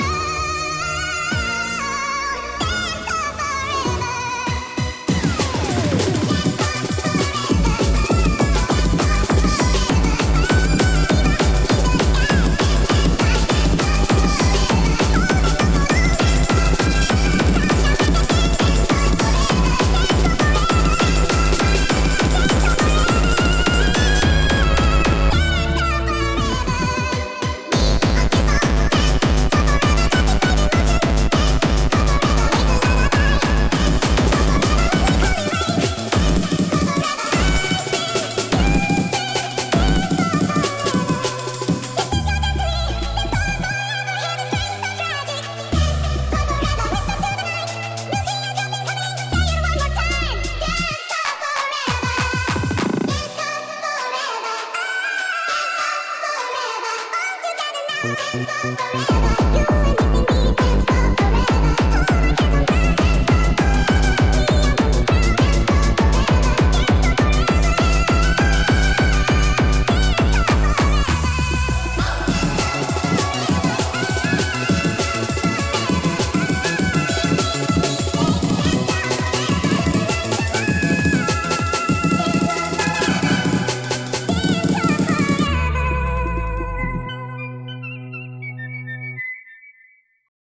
dancecore_forever_unfinished_normal_voice.mp3